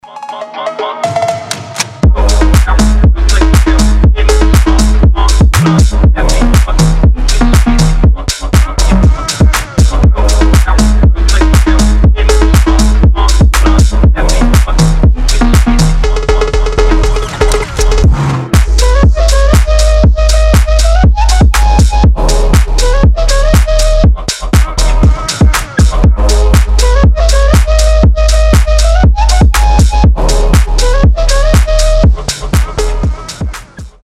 басы
восточные
арабские
фонк
Бодрый phonk с восточными нотами